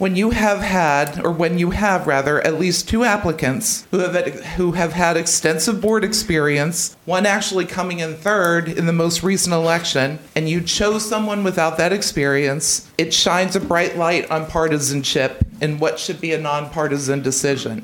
The Allegany County Commissioners had a full meeting last Thursday with many constituents signing in to speak on various subjects.